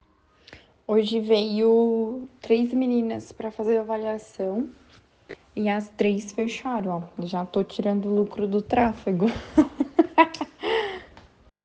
Depoimentos